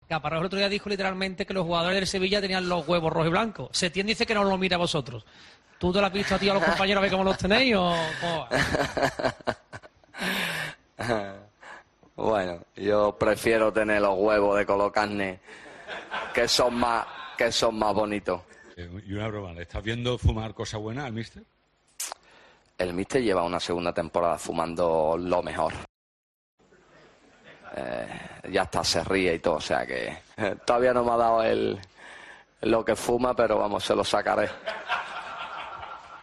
Joaquín Sánchez, jugador del Betis, responde con humor a Joaquín Caparrós, que aseguró hace unos días que sus futbolistas tienen los huevos rojos y blancos.